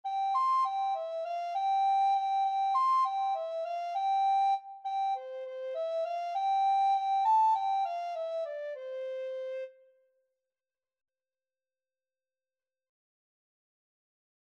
World Trad. Pimpon (Spanish Traditional) Soprano (Descant) Recorder version
C major (Sounding Pitch) (View more C major Music for Recorder )
2/4 (View more 2/4 Music)
Quick
C6-C7
World (View more World Recorder Music)
pimpon_REC.mp3